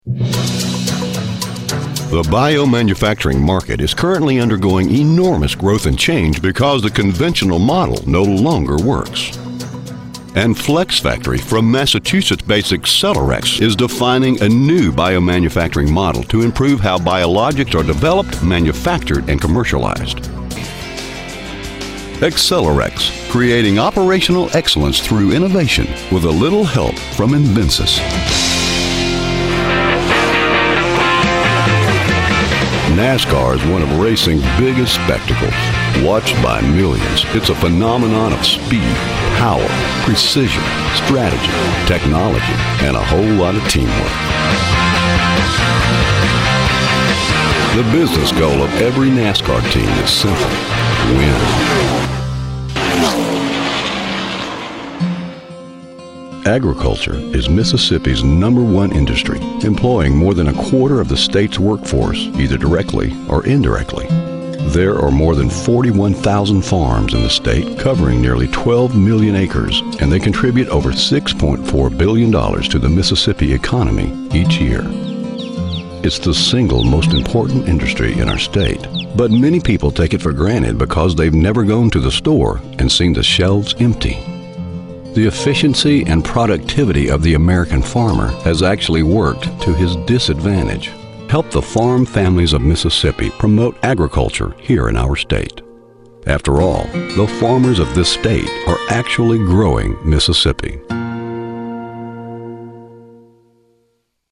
Narration Demo
Slight southern, Texas accent
Middle Aged